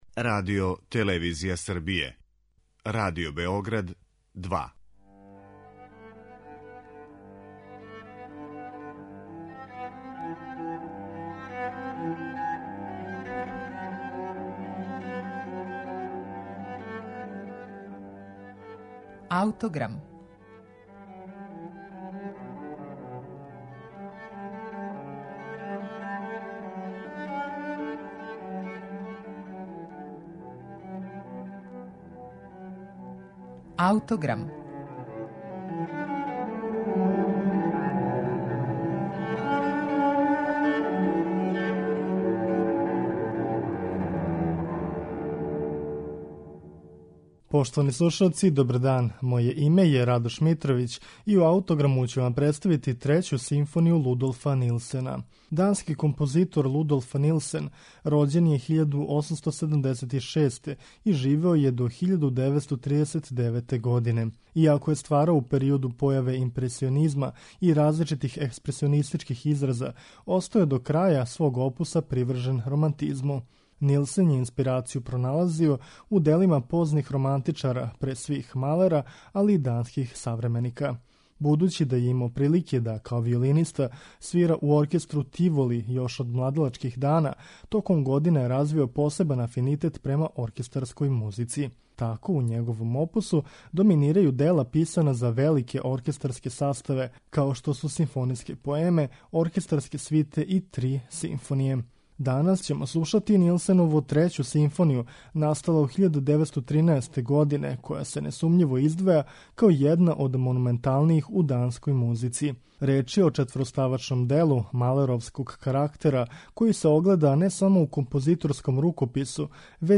малеровског карактера
симфонијског оркестра